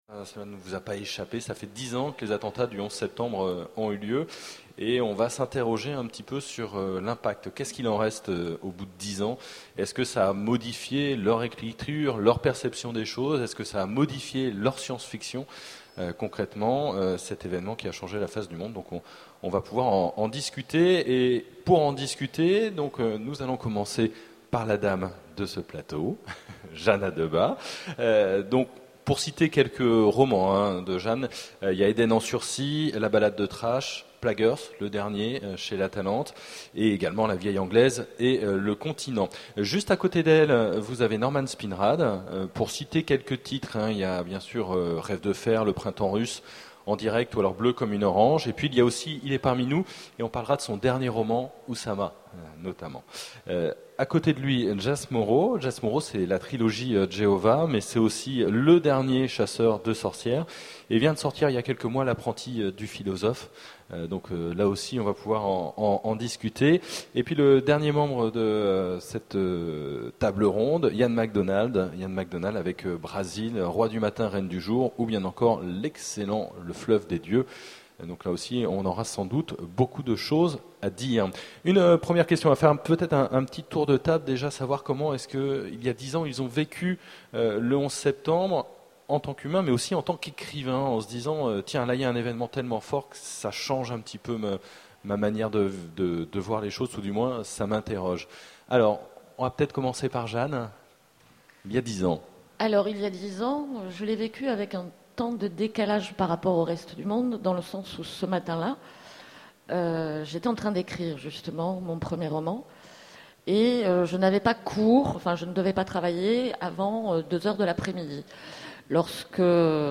Utopiales 2011 : Conférence 11 septembre, 10 ans après